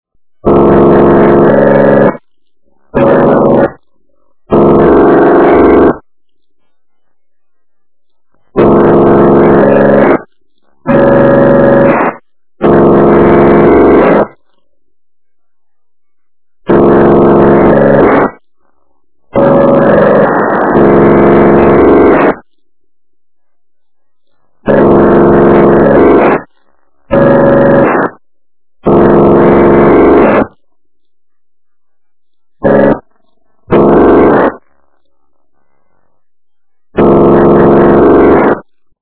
Цифровые сигналы на 433.92 МГц